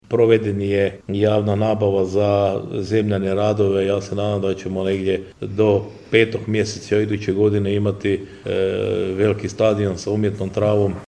Prelog će iduće godine u listopadu biti domaćin Prvenstvu Hrvatske u karateu, najavljeno je ovog tjedna na održanoj konferenciji za medije u gradskoj vijećnici.